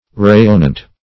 rayonnant - definition of rayonnant - synonyms, pronunciation, spelling from Free Dictionary